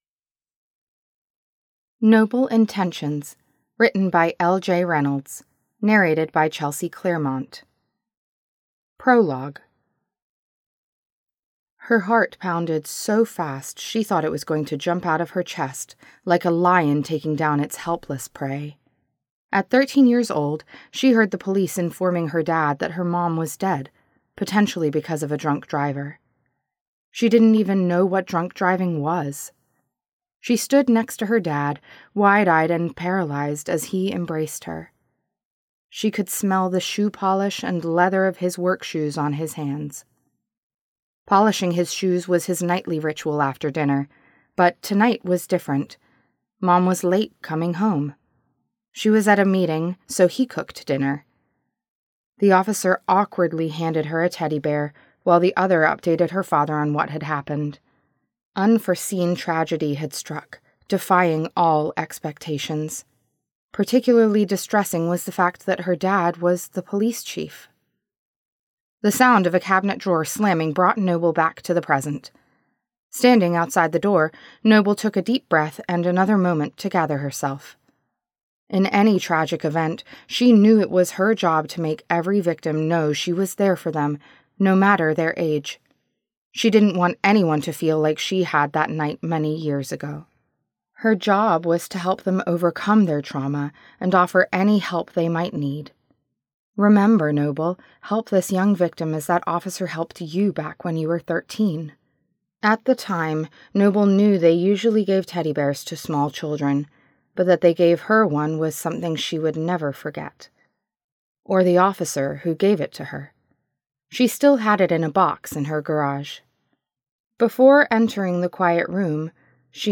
Nobel Intentions By LJ Reynolds [Audiobook]